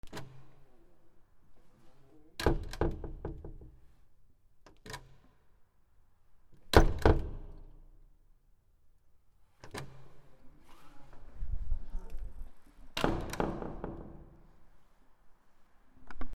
扉
/ K｜フォーリー(開閉) / K05 ｜ドア(扉)